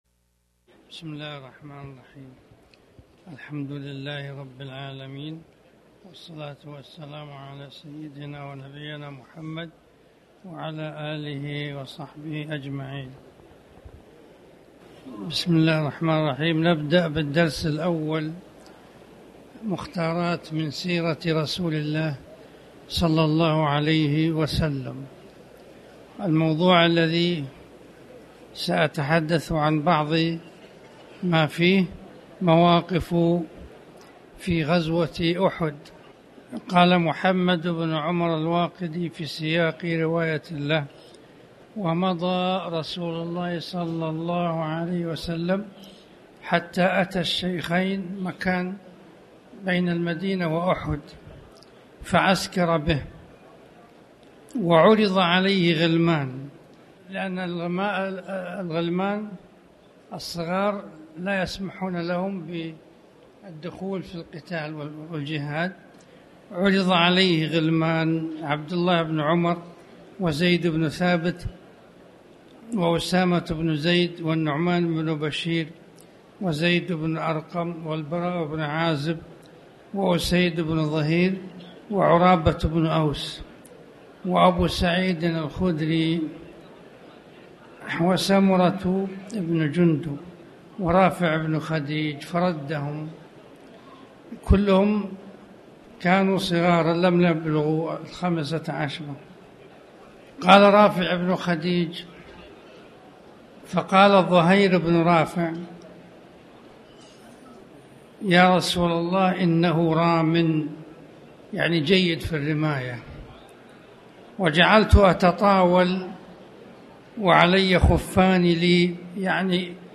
تاريخ النشر ٦ ربيع الثاني ١٤٣٩ هـ المكان: المسجد الحرام الشيخ